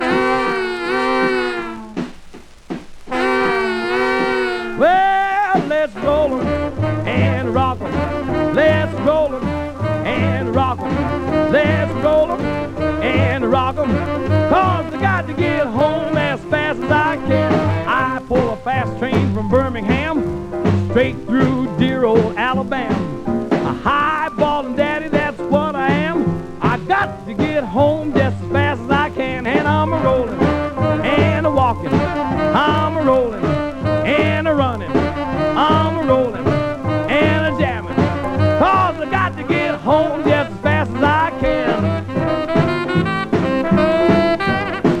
Jazz, Rhythm & Blues, Jump　Sweden　12inchレコード　33rpm　Mono